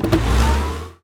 car-engine-load-reverse-3.ogg